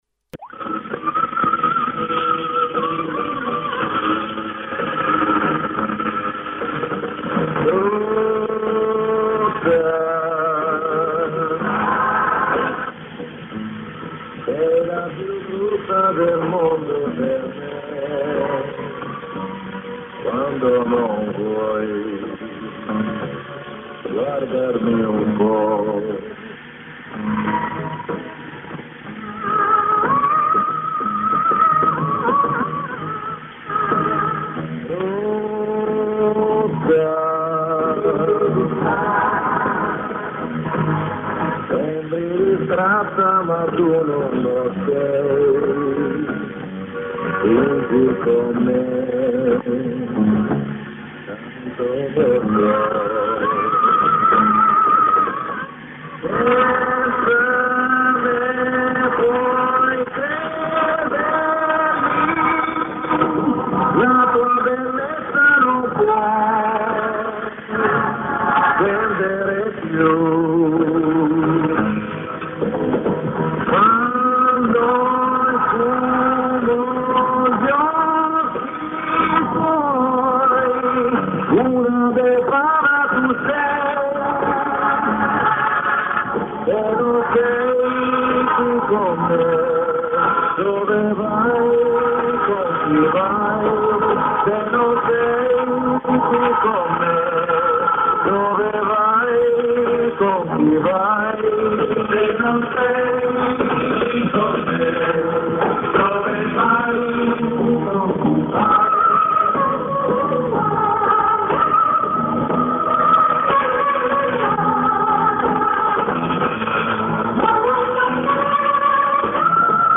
Запись с бобины.